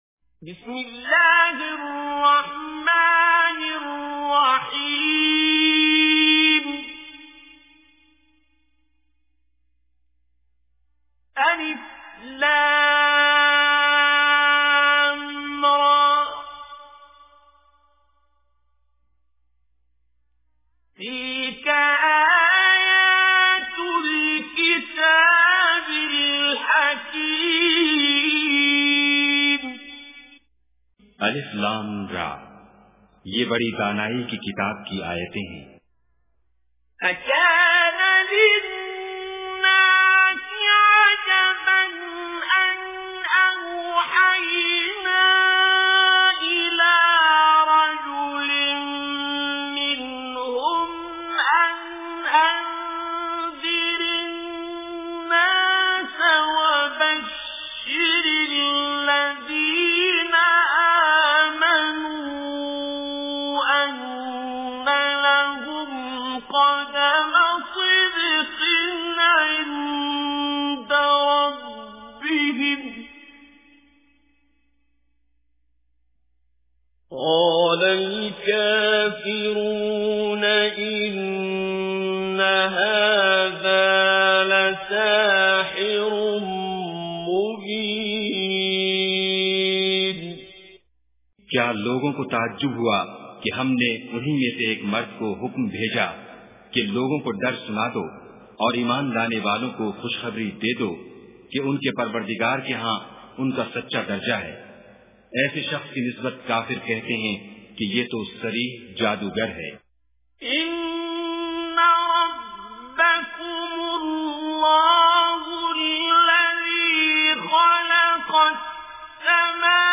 Tilawat in the voice of Qari Abdul Basit As Samad.